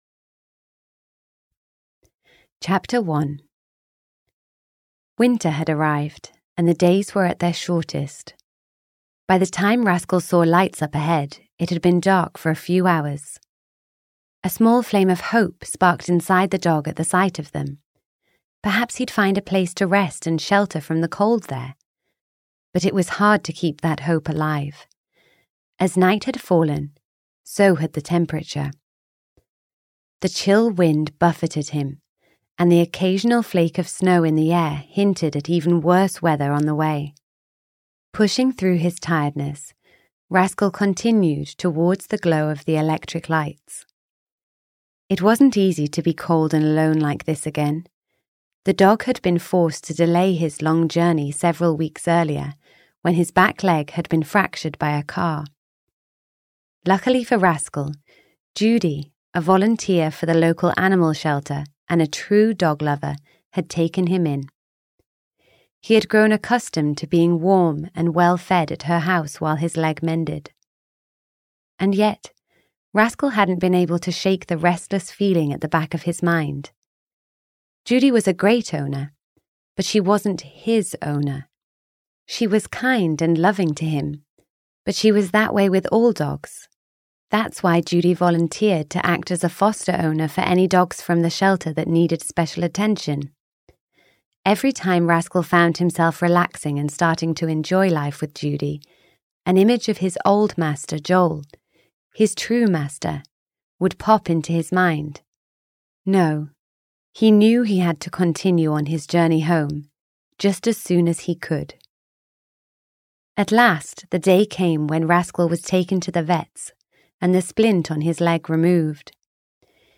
Ukázka z knihy
rascal-6-racing-against-time-en-audiokniha